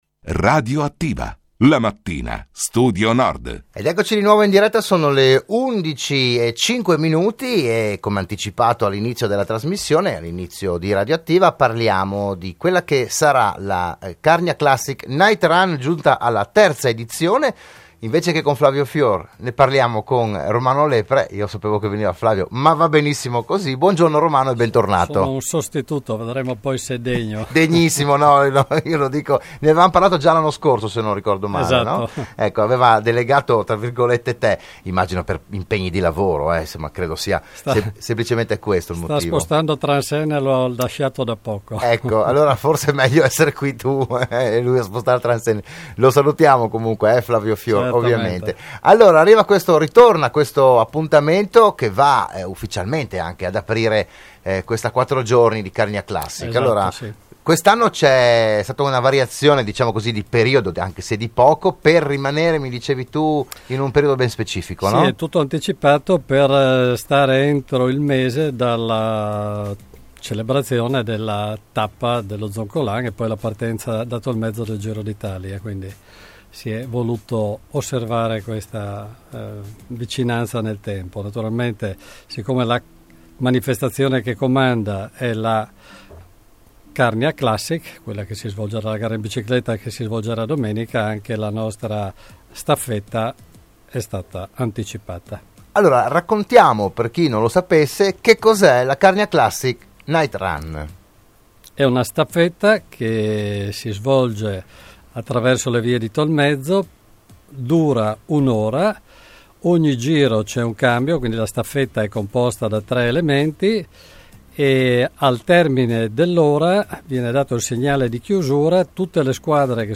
Appuntamento con la staffetta podistica di Tolmezzo giovedì 21 giugno.